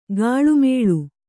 ♪ gāḷu mēḷu